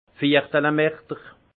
Prononciation 68 Munster